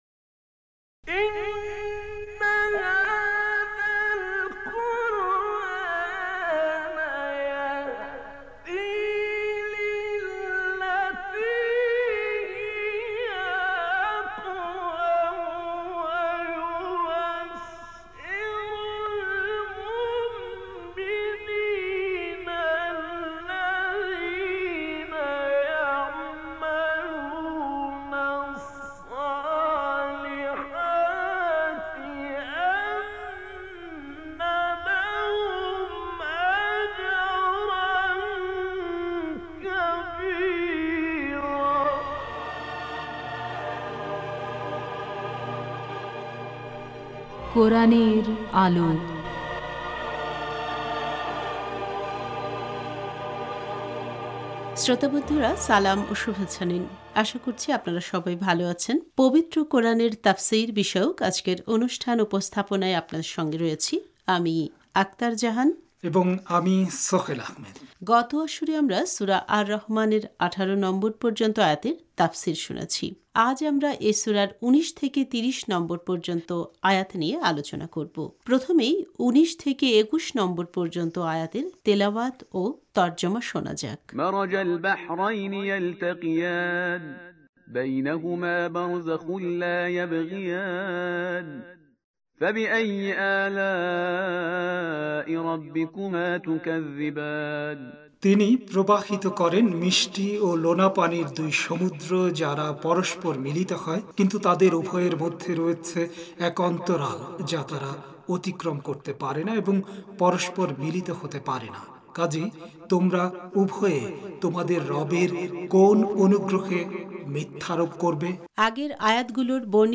প্রথমেই ১৯ থেকে ২১ নম্বর পর্যন্ত আয়াতের তেলাওয়াত ও তর্জমা শোনা যাক: